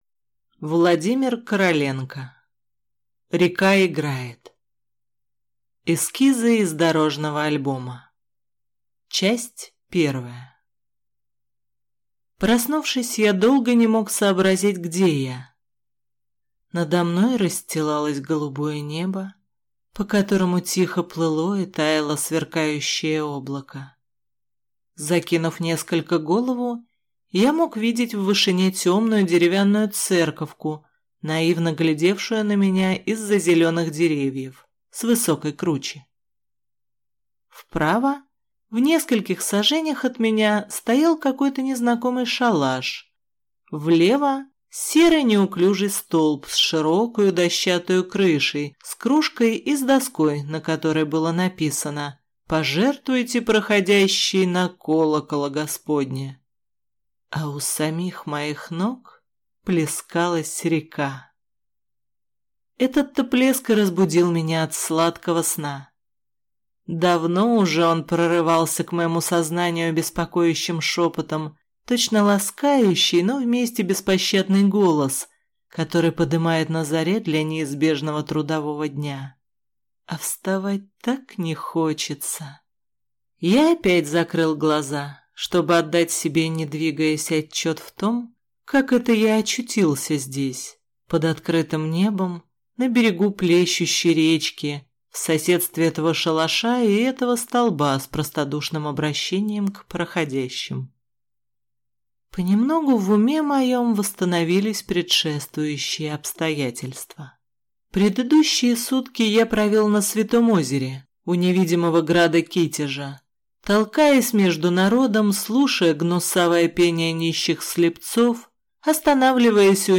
Аудиокнига Река играет | Библиотека аудиокниг